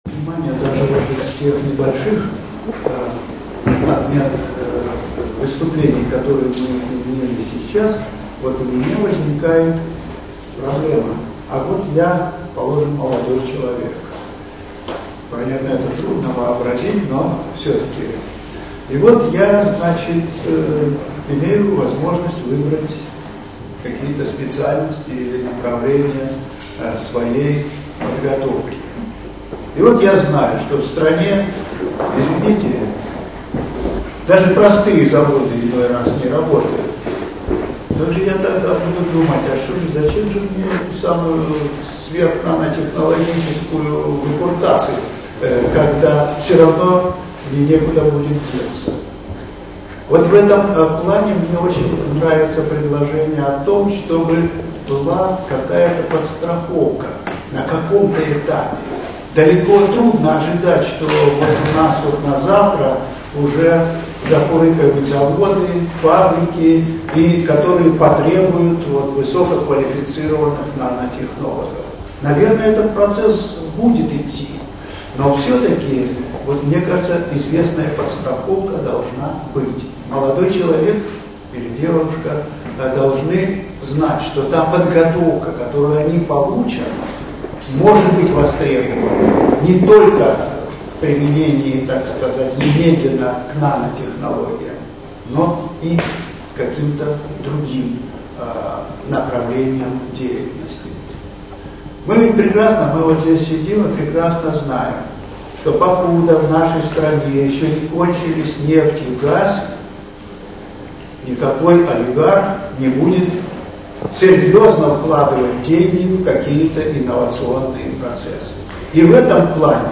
Аудиозапись выступлений